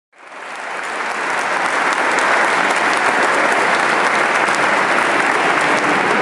掌声1
描述：这是在一次跨文化活动中用我的佳能摄像机拍的。
Tag: 鼓掌 鼓掌 鼓掌 观众